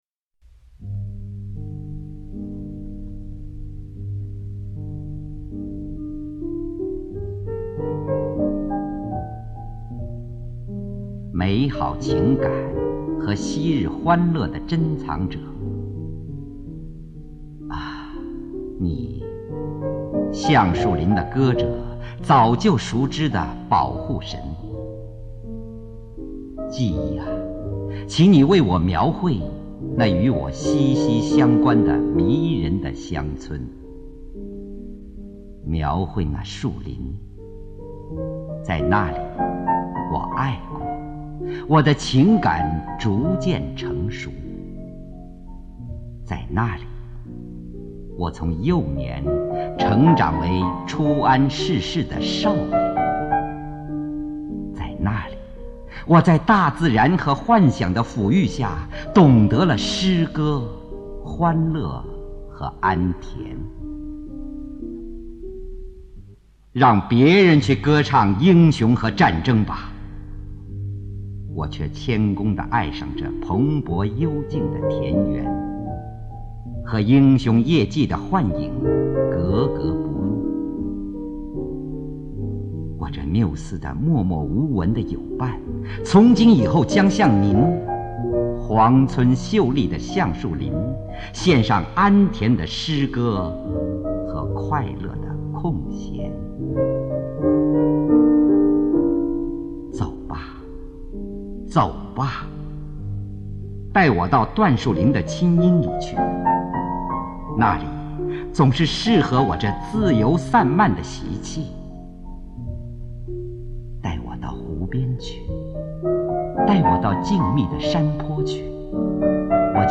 普希金诗配乐朗诵
（由录音带转录）